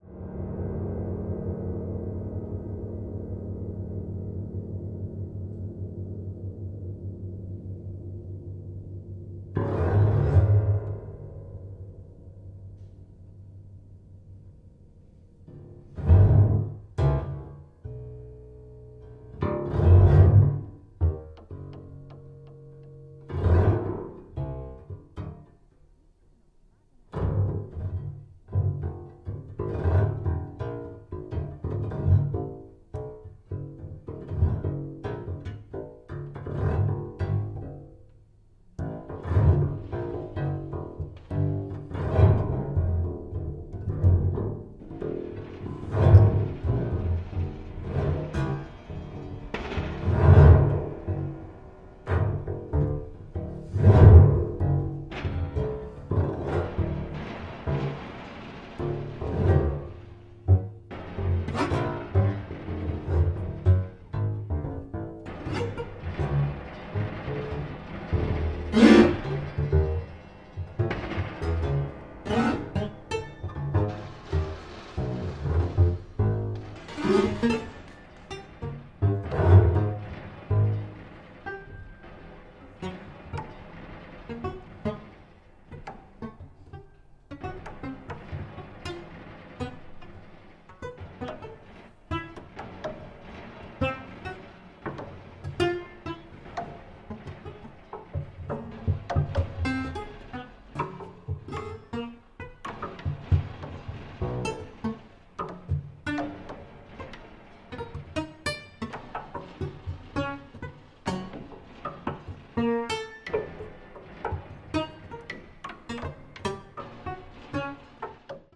piano, clavichord, zither, electronics